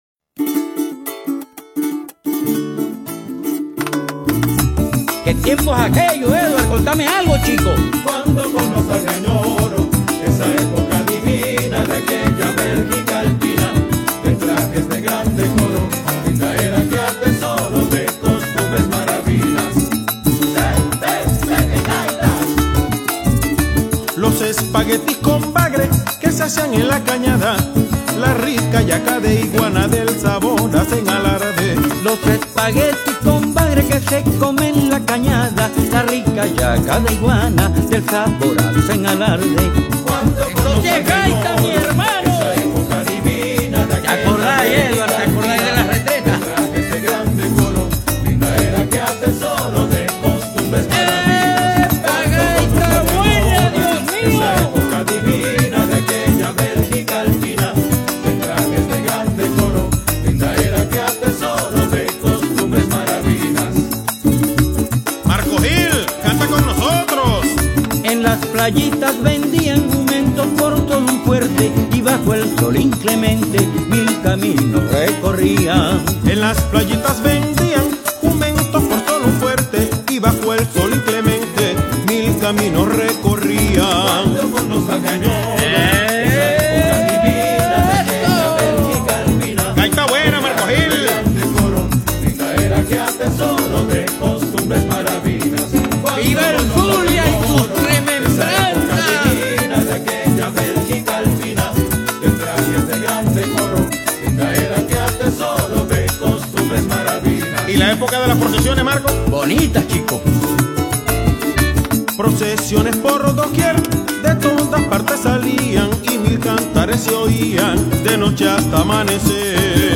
Gaitas 2011 en aacPlus